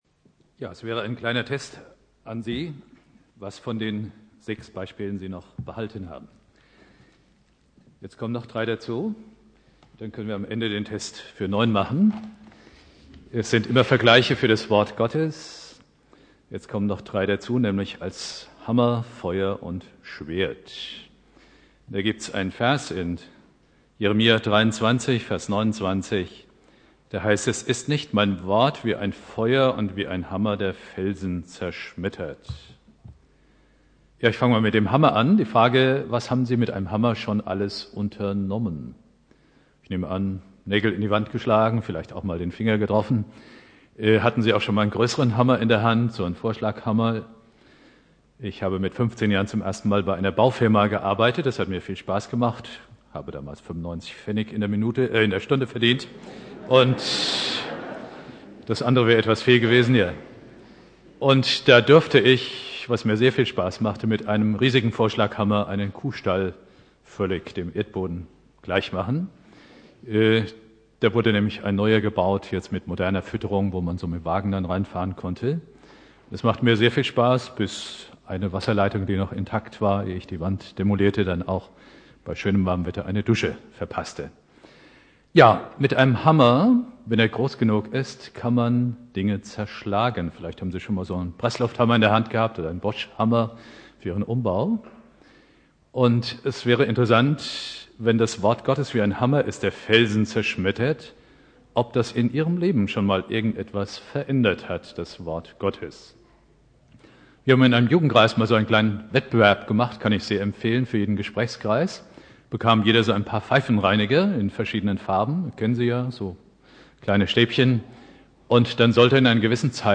Predigt
Gottes Wort: "Hammer, Feuer, Schwert" (Ökumenischer Gottesdienst) Bibeltext